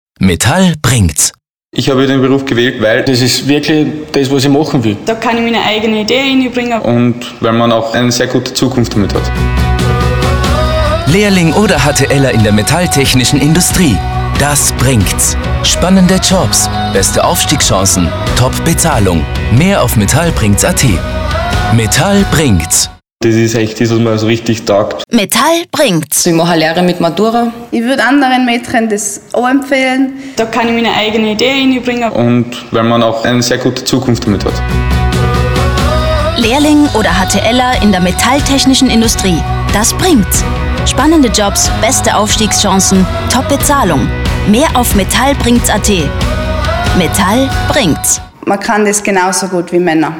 ALLES MÖGLICHE Berufsinformation-Videos und Radiospot Genug gelesen!
Metalltechnische_Industrie_2017_Spot_BEIDE_MannFirst.mp3